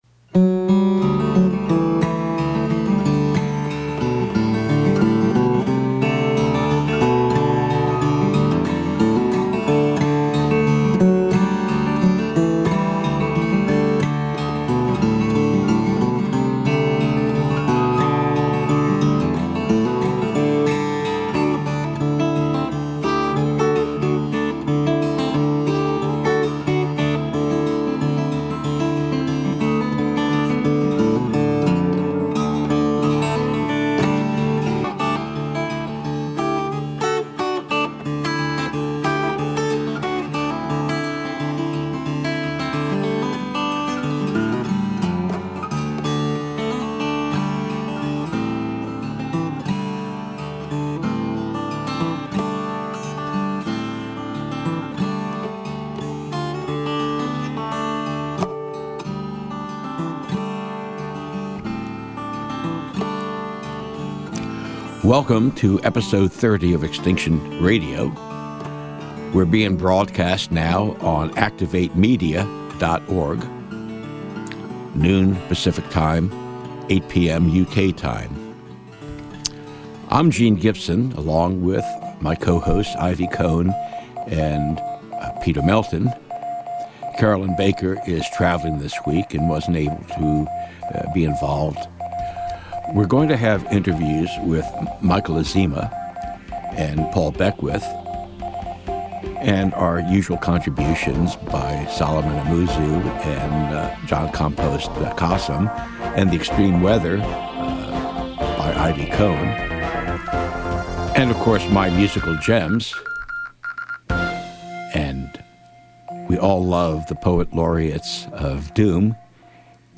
Conversation
Poetry